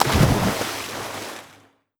waterimpact.wav